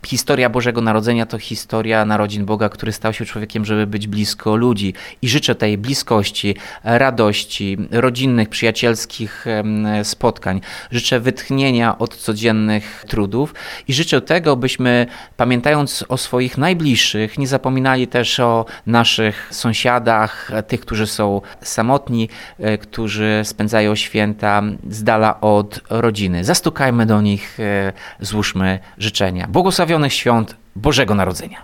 Świąteczne życzenia złożył mieszkańcom oraz gościom prezydent Ełku Tomasz Andrukiewicz: